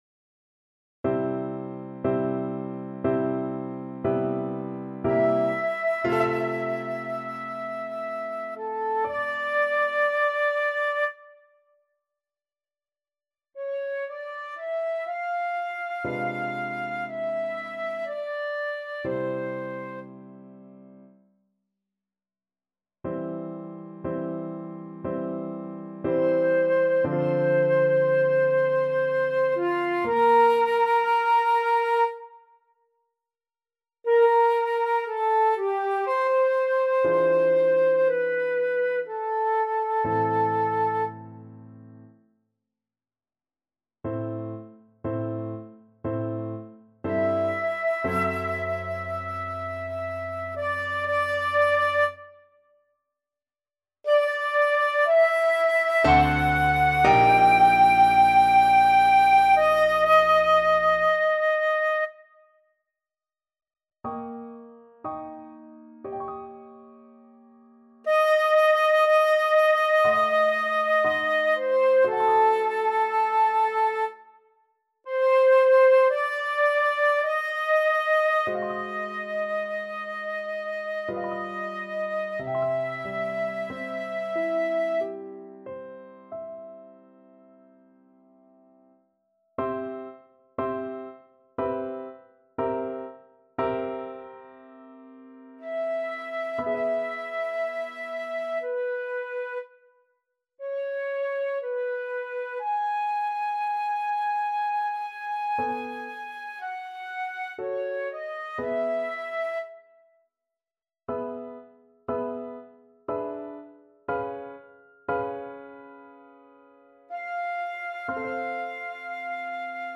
3/4 (View more 3/4 Music)
~ = 60 Langsam, leidenschaftlich
Classical (View more Classical Flute Music)